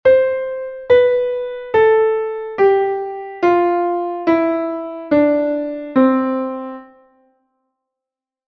Lidio
do-si-la-sol-fa-mi-re-do